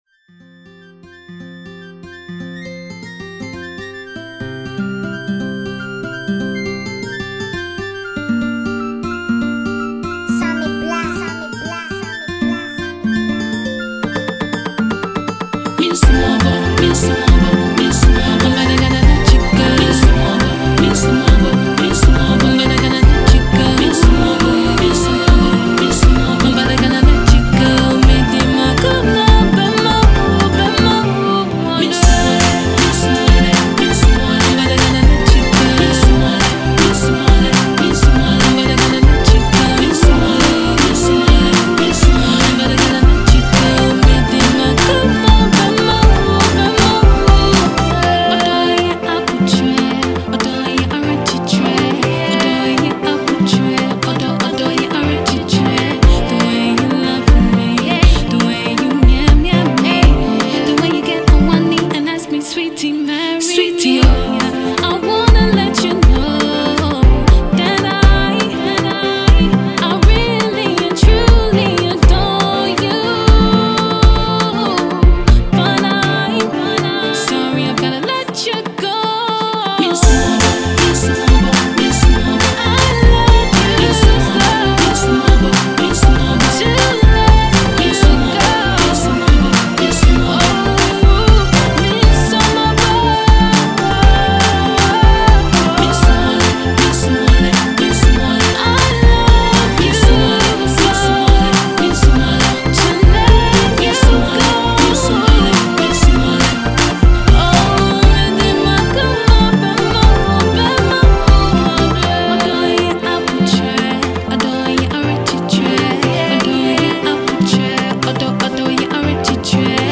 heart-warming love song
one of their most prolific rappers